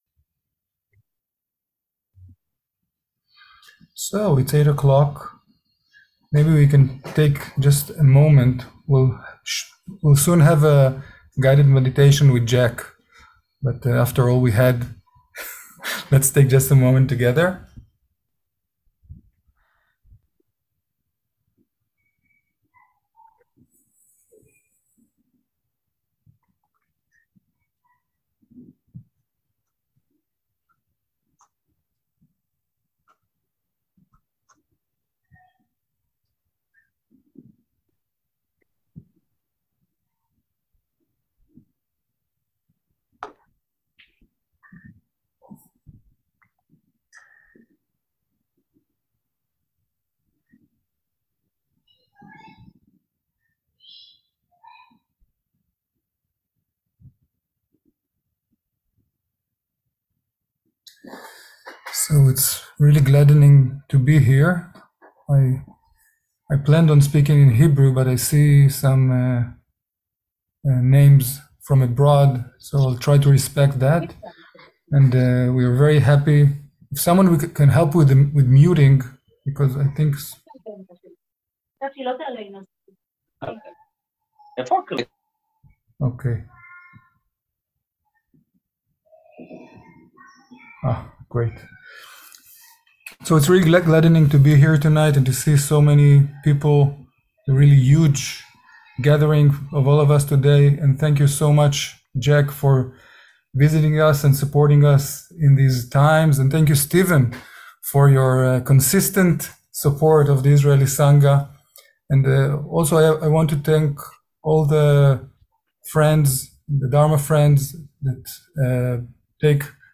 מפגש זום
שיחות דהרמה